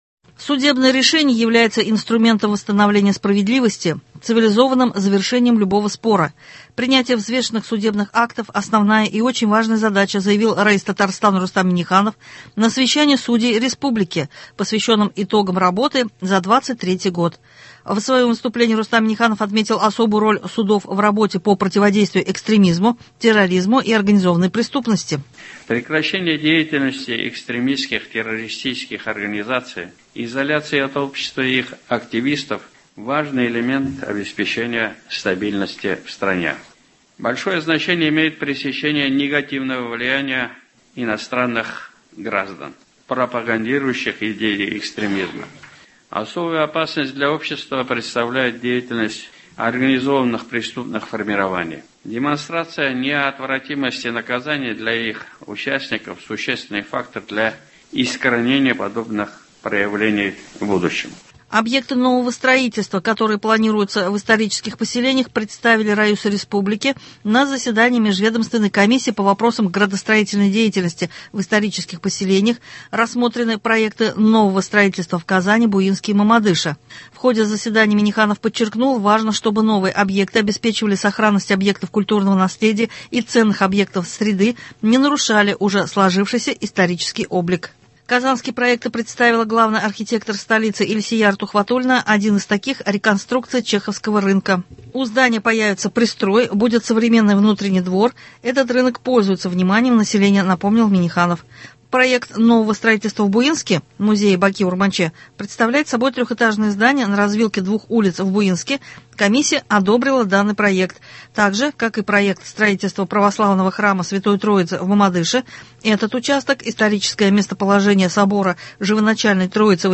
Новости (28.02.24)